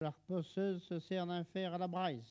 Localisation Bouin
Catégorie Locution